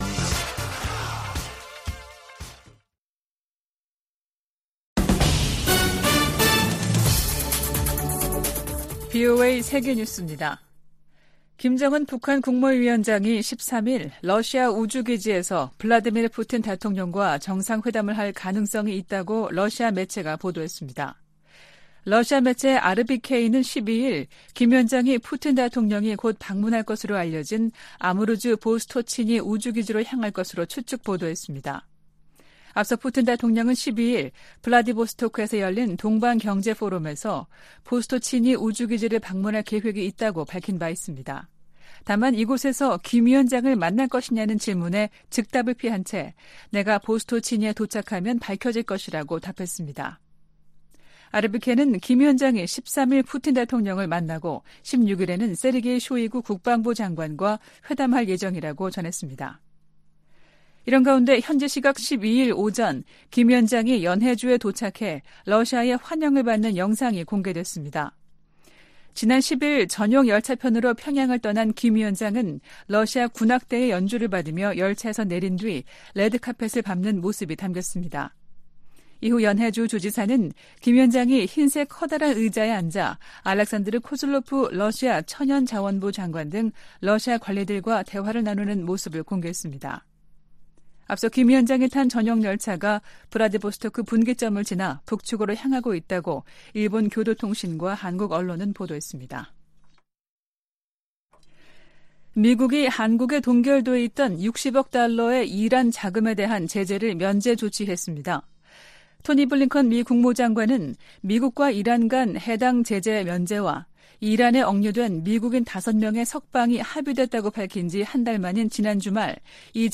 VOA 한국어 아침 뉴스 프로그램 '워싱턴 뉴스 광장' 2023년 9월 13일 방송입니다. 김정은 북한 국무위원장이 러시아 방문에 군부 실세들을 대동하고 있는 것으로 알려졌습니다. 미국 정부는 북러 정상회담을 면밀히 주시한다고 밝히고 북한이 러시아에 무기를 제공하지 않겠다고 한 약속을 지킬 것을 촉구했습니다. 북한 풍계리에서 추가 핵실험을 지원할 수 있는 활동 징후가 계속 포착되고 있다고 국제원자력기구(IAEA) 사무총장이 밝혔습니다.